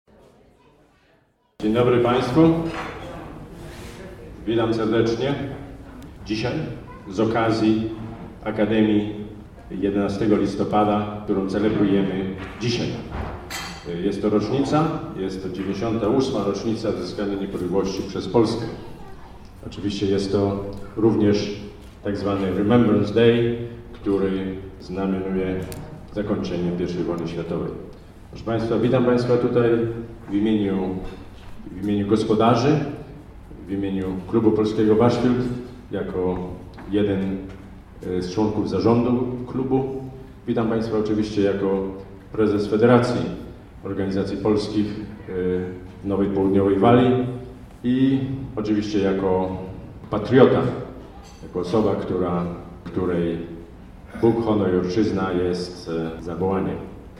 fragment powitania